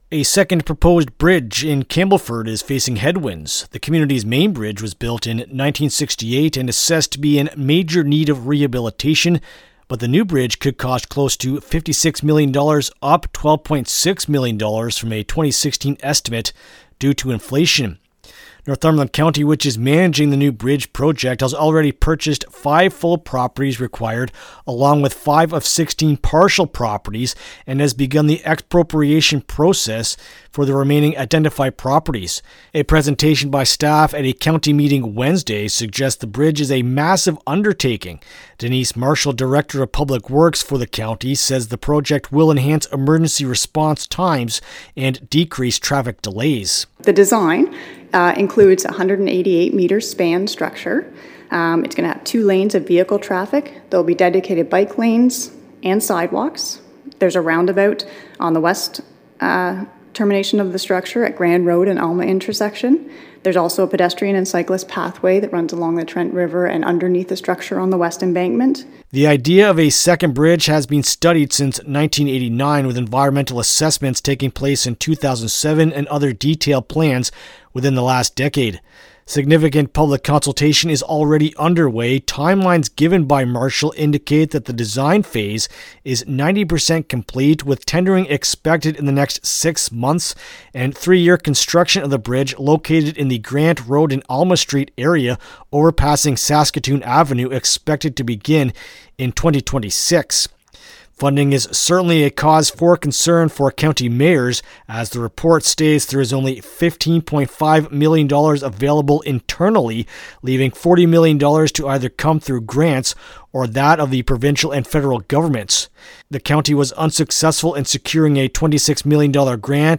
Campbellford-Second-Bridge-Report-LJI.mp3